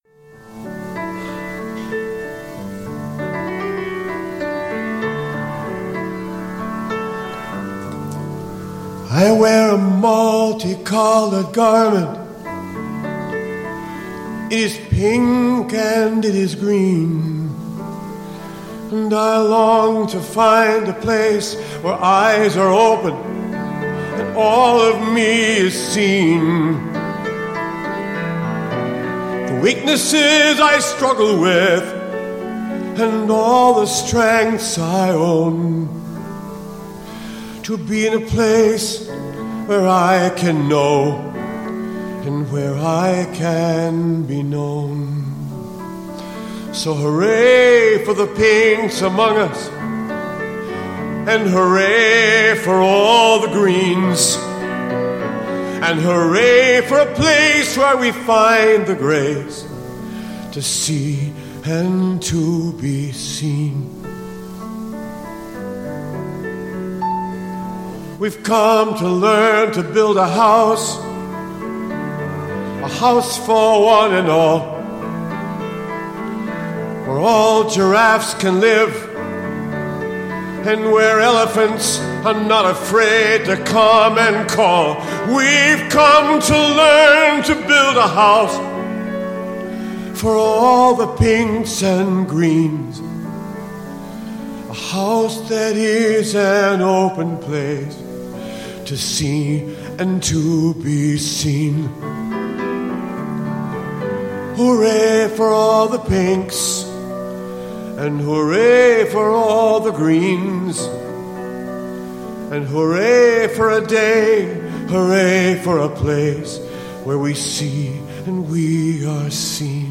improvised song of prayer
In September 2016 participants gathered at the Universal Design for Worship seminar at Calvin College. Singer-songwriter